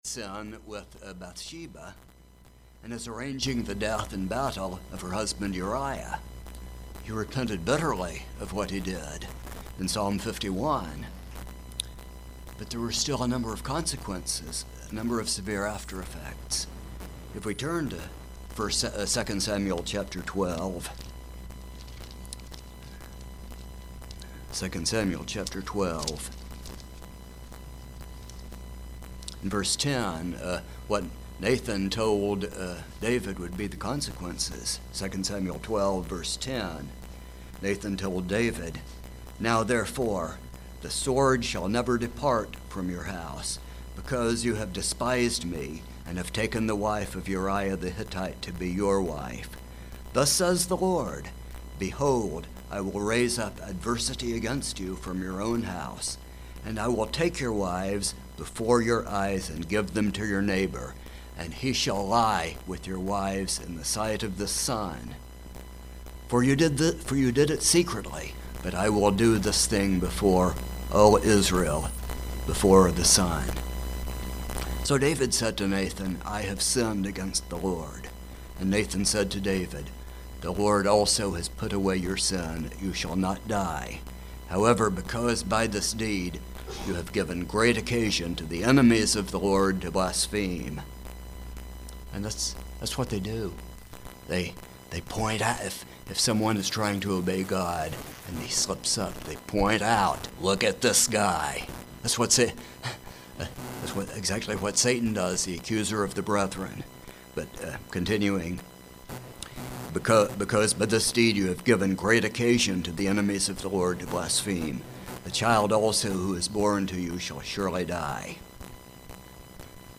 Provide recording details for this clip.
Given in Kingsport, TN